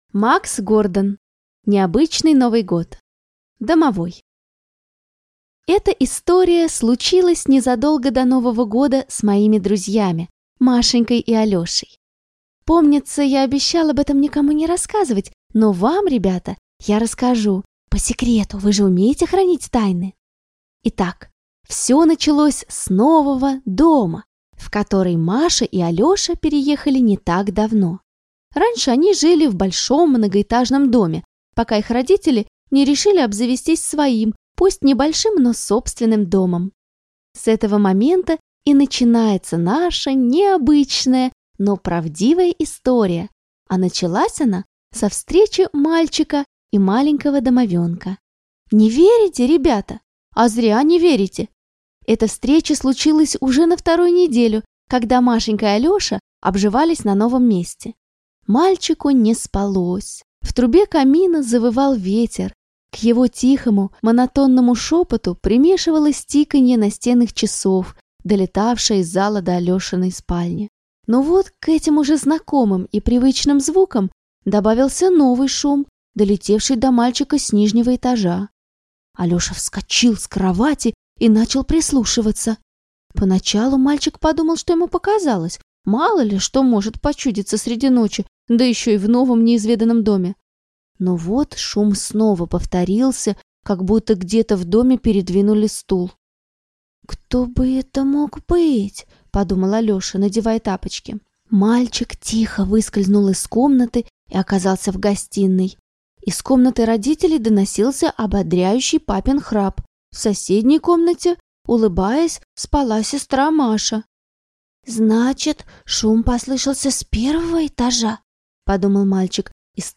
Аудиокнига Необычный Новый Год | Библиотека аудиокниг